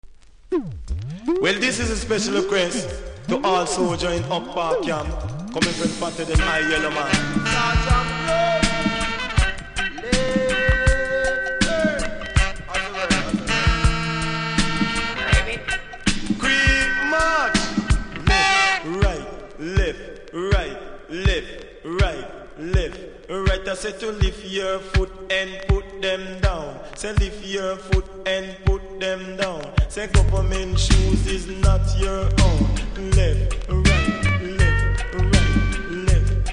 REGGAE 80'S